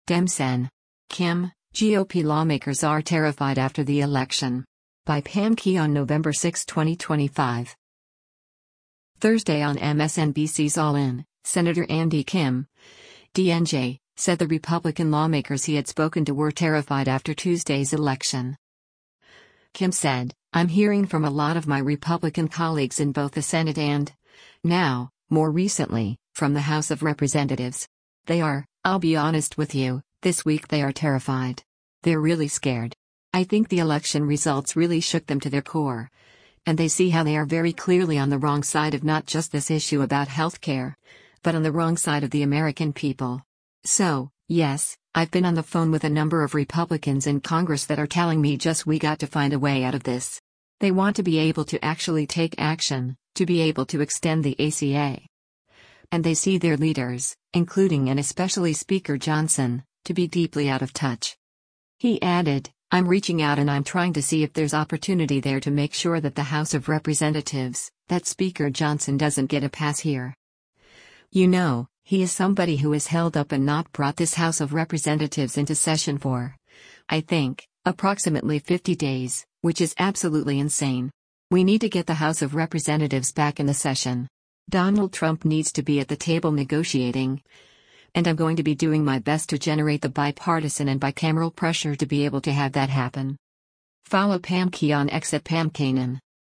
Thursday on MSNBC’s “All In,” Sen. Andy Kim (D-NJ) said the Republican lawmakers he had spoken to were “terrified” after Tuesday’s election.